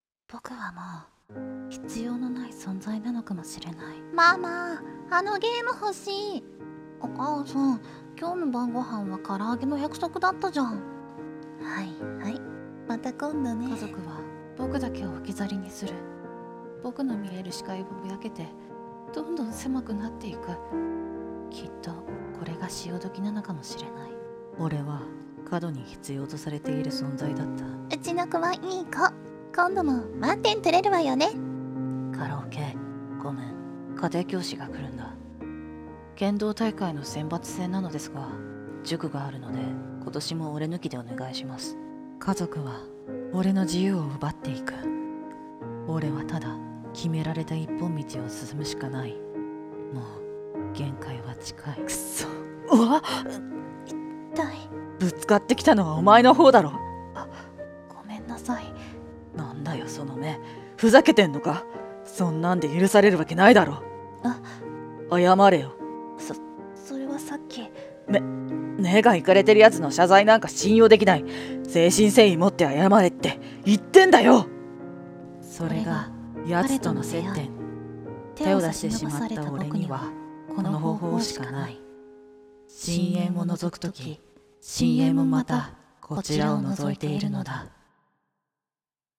BL声劇 「深淵を覗いたら」